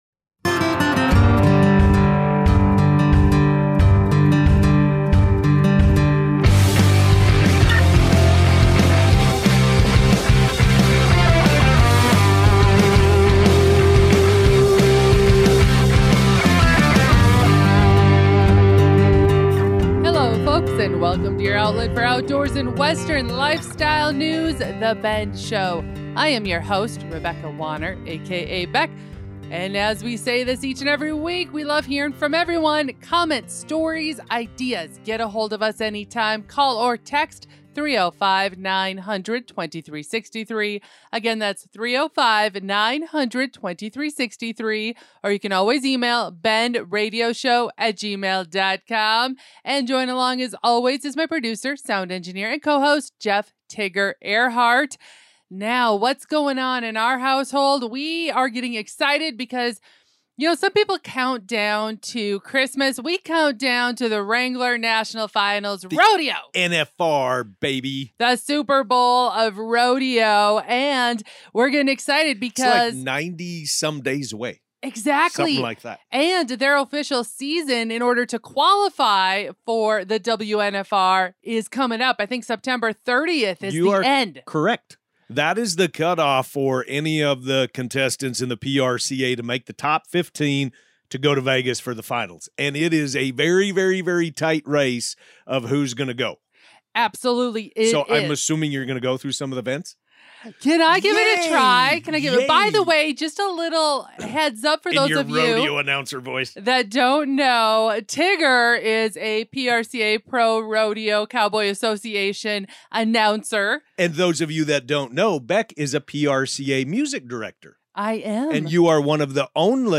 This week, we're saddling up for an incredible conversation with Froelich Legacy Quarter Horses!